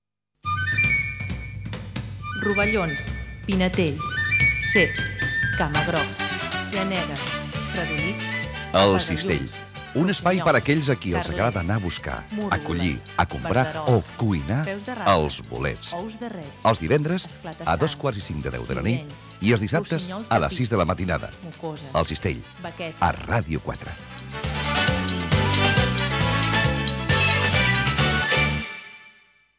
Promoció del programa
FM